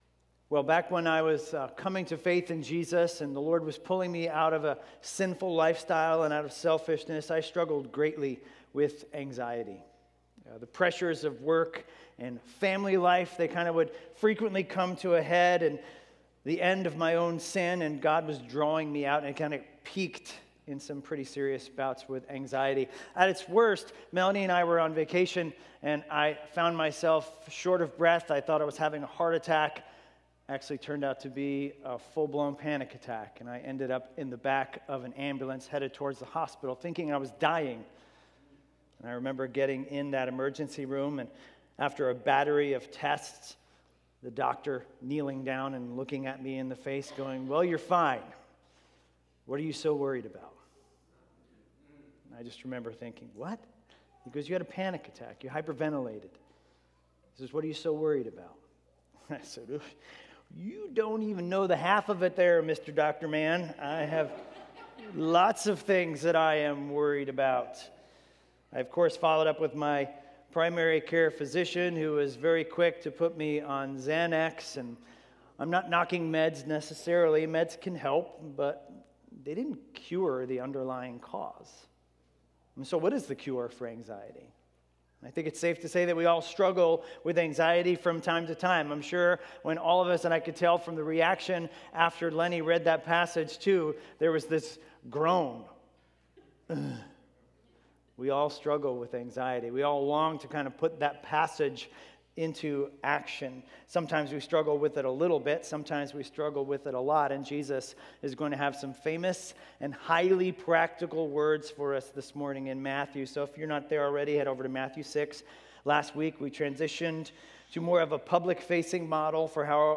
A message from the series "Psalms." In Psalm 38 we learn that only God can resolve the effects of sin.